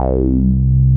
RUBBER C3 M.wav